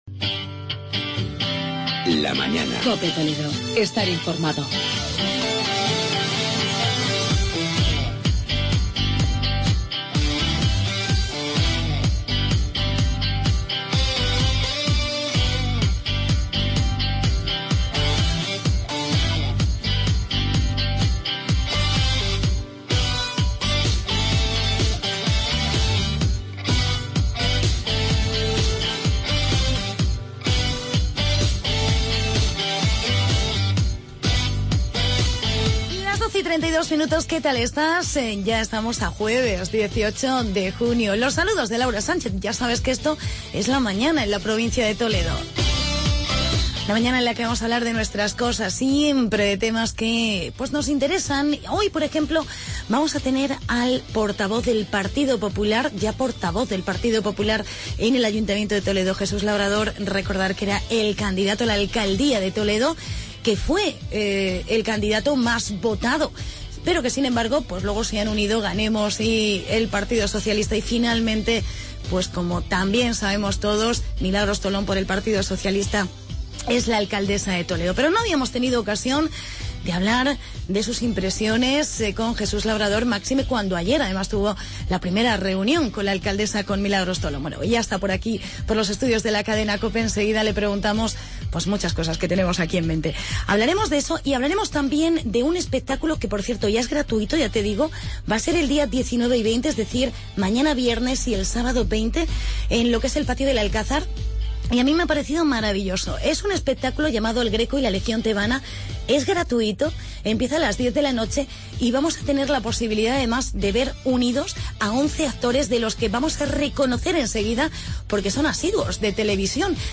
Entrevistamos al portavoz del Grupo Municipal del PP en Toledo, Jesús Labrador.